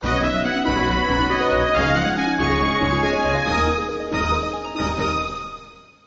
［BGM・SE素材］